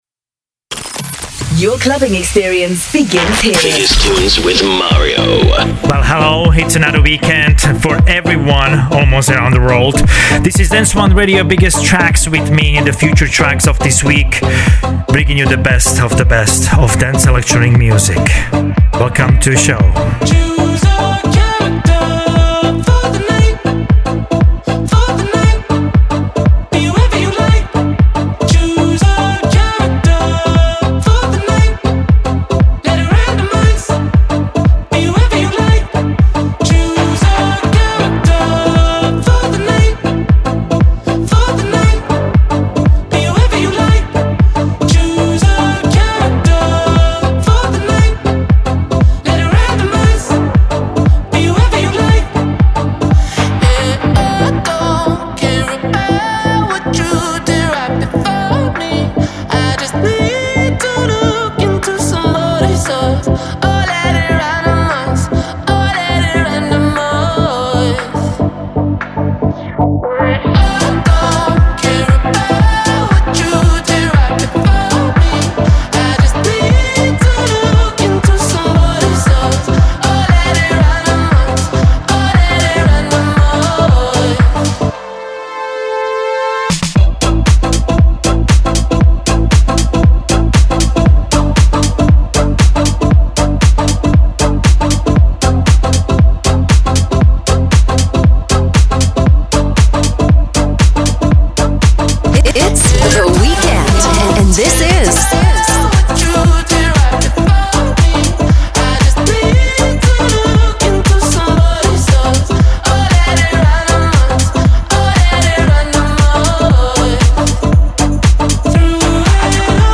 Dance weapons that rule the dance and electronic scene